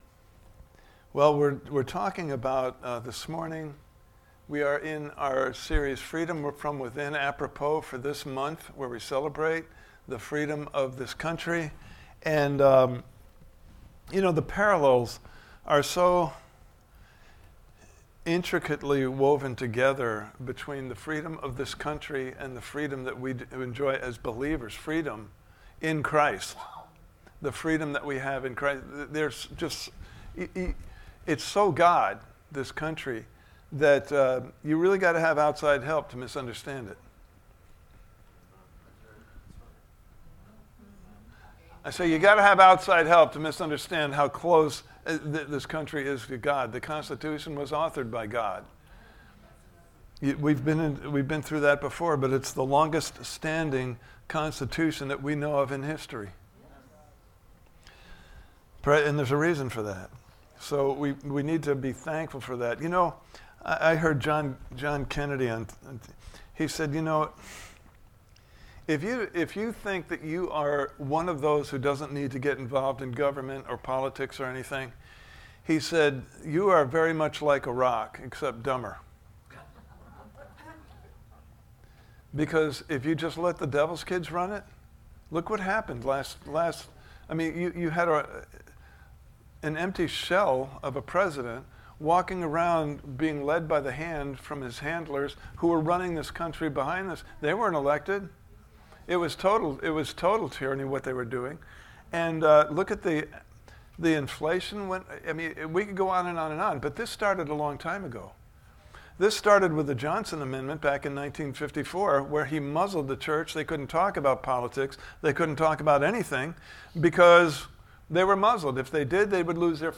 Freedom From Within Service Type: Sunday Morning Service « Part 1